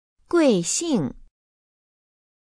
guìxìng